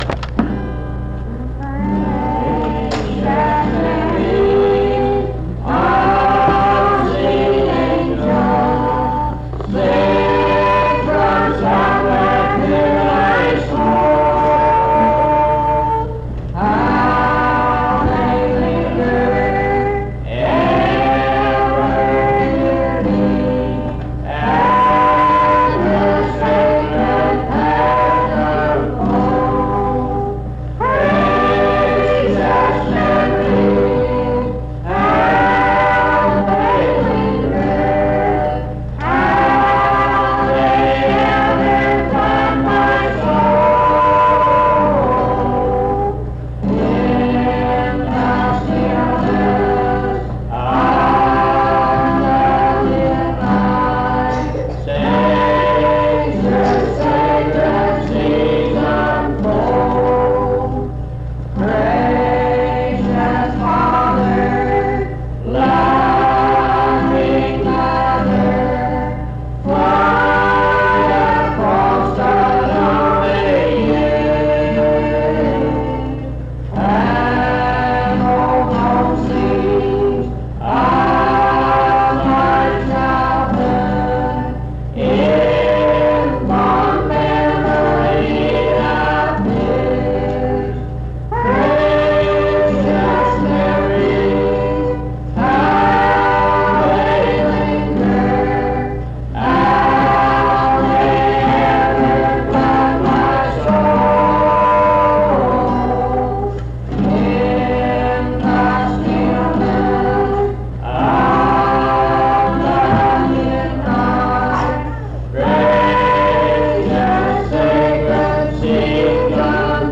Precious Memories Item e7964a6a8a2955a9cd2ac0443e971d98d5068f5b.mp3 Title Precious Memories Creator Calvary Methodist Church Choir Description This recording is from the Monongalia Tri-District Sing. Highland Park Methodist Church, Morgantown, Monongalia County, WV.